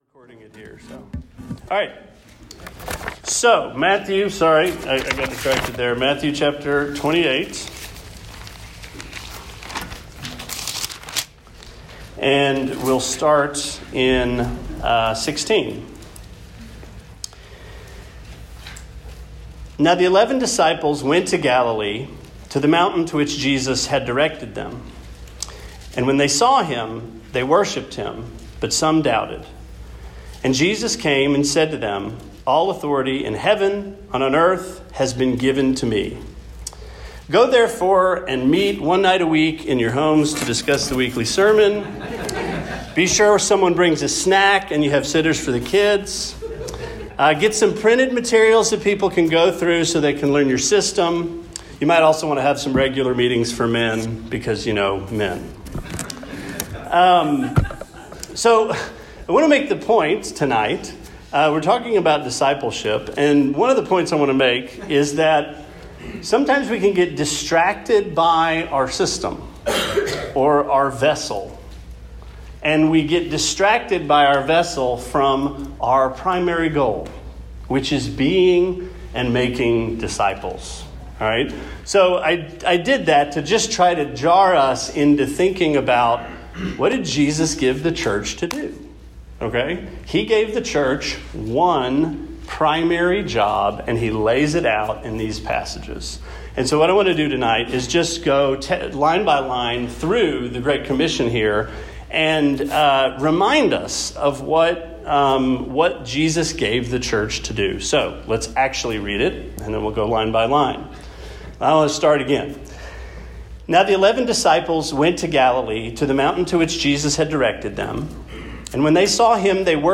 Sermon 08/23: Discipleship Part 1